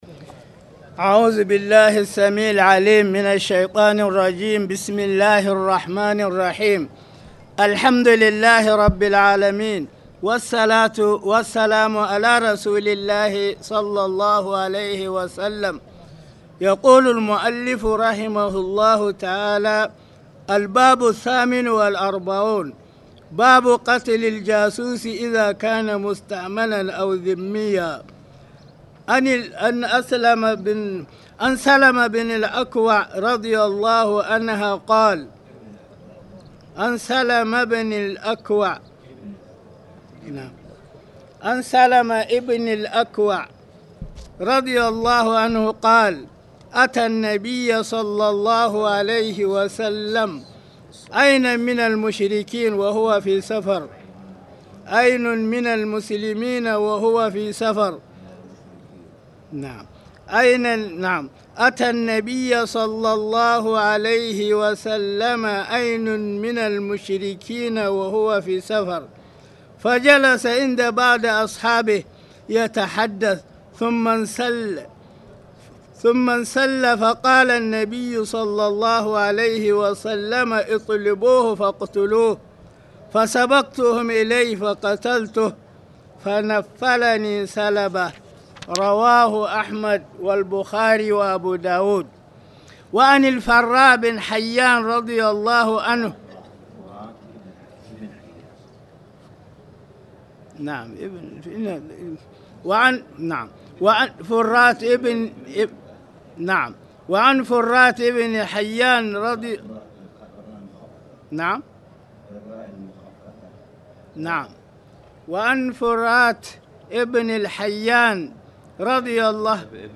تاريخ النشر ٣ جمادى الأولى ١٤٣٨ هـ المكان: المسجد الحرام الشيخ: معالي الشيخ أ.د. صالح بن عبدالله بن حميد معالي الشيخ أ.د. صالح بن عبدالله بن حميد باب قتل الجاسوس إذا كان مستأمنا أوذميا